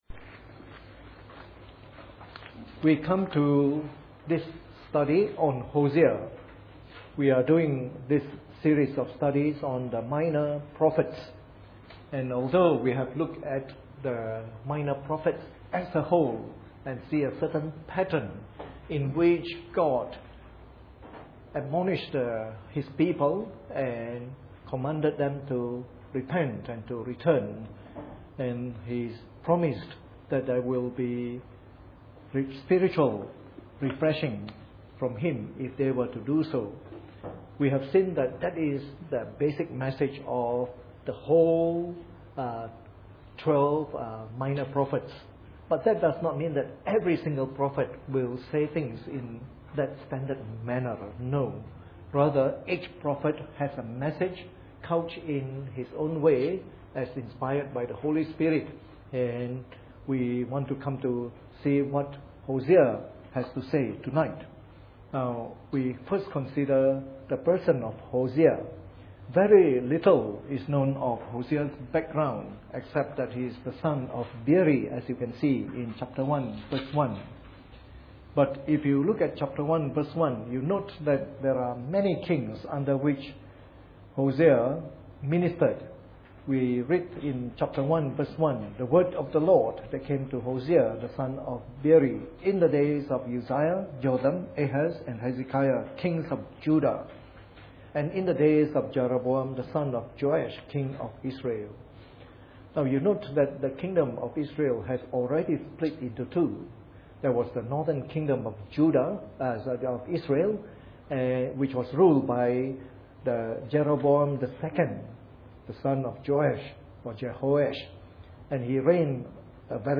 Preached on the 26th of September 2012 during the Bible Study from our new series entitled “The Minor Prophets.”